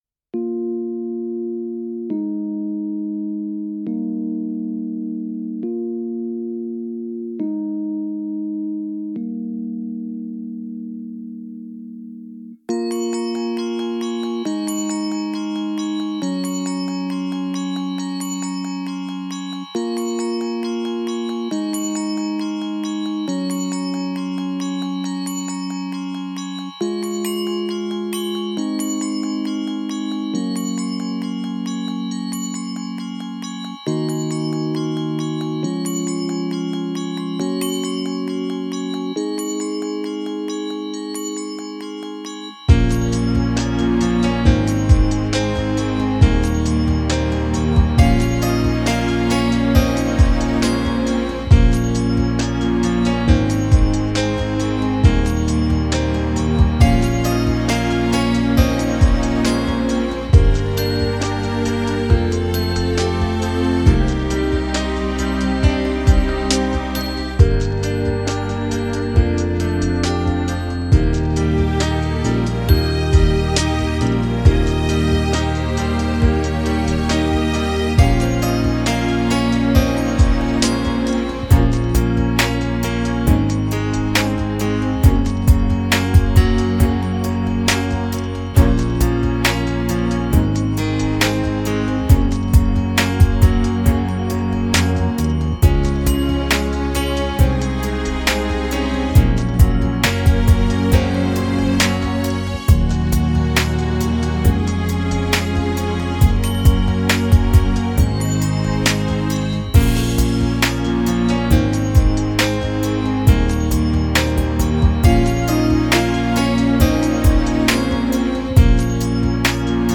I See You (Custom Backing Track) | Ipswich Hospital Community Choir